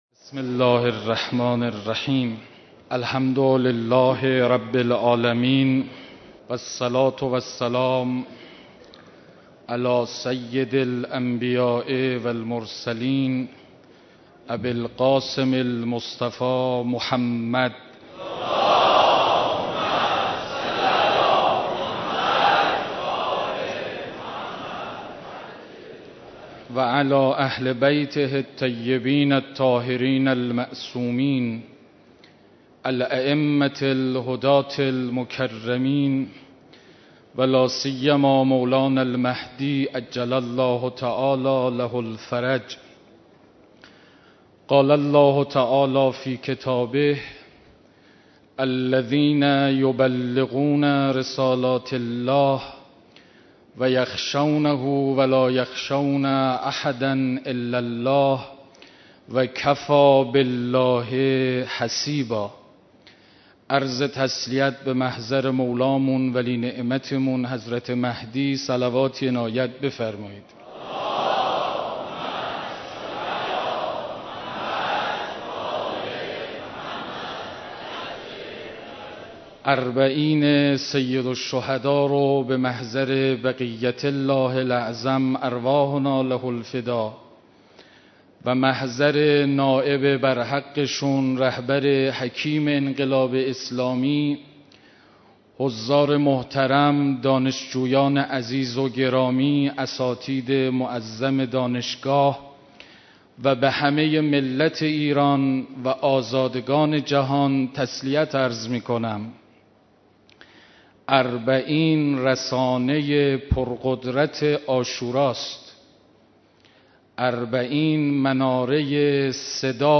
مراسم عزاداری اربعین حسینی علیه‌السلام
مراسم عزاداری اربعین حسینی علیه‌السلام برگزار شد
سخنرانی